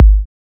edm-kick-36.wav